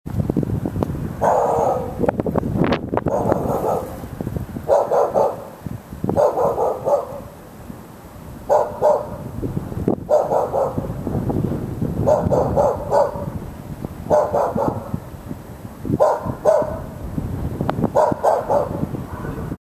Nervous Dog Barking Sound Button - Free Download & Play
Dog Barking Sound580 views